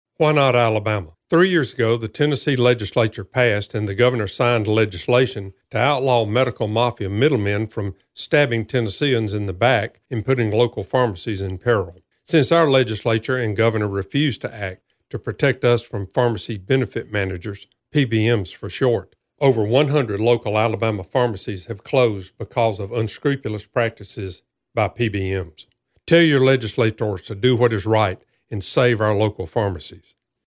Why Not Alabama Radio Ad - Listen Here!
This audio public service announcement is already being broadcast on several live radio stations across Alabama including Rock 103 in Columbus which covers East Alabama and was played eight times during the Auburn game.